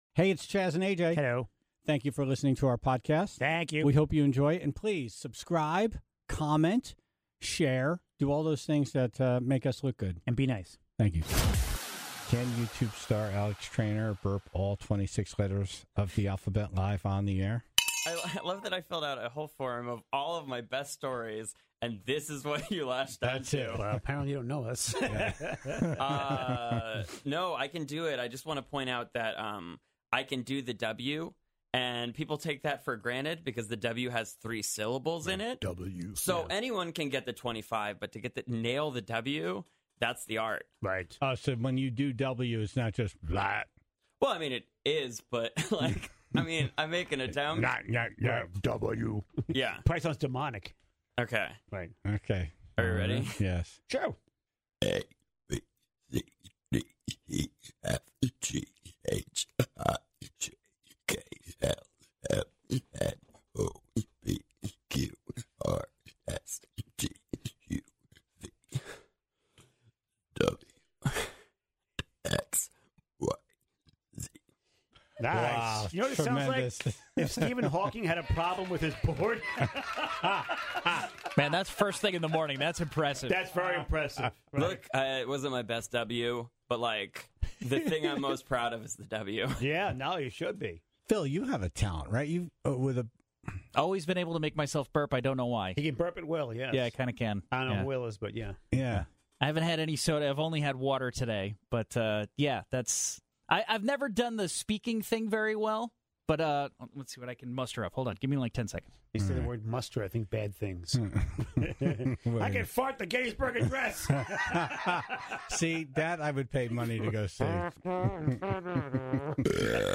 and showcased his ability to burp the entire alphabet, even that tricky "W."